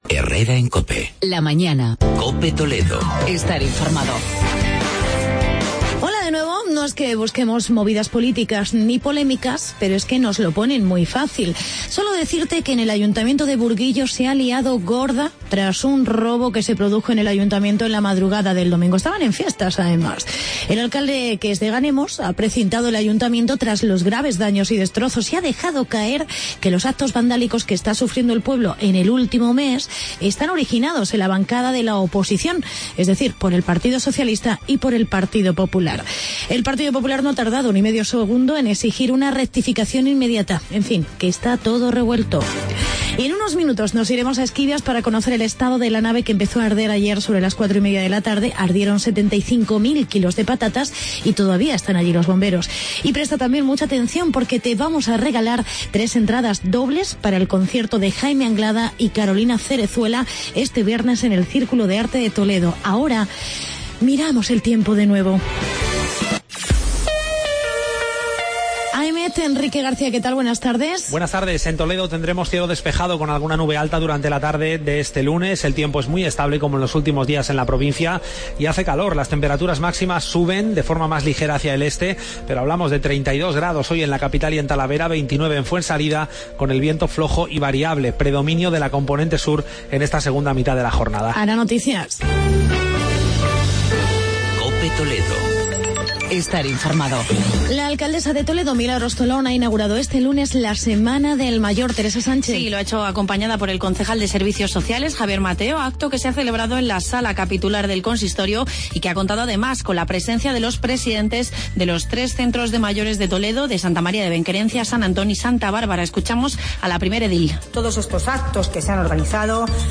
Actualidad y entrevista con la alcaldesa de Esquivias, para conocer la última hora del incencio de una nave de patatas en la localidad.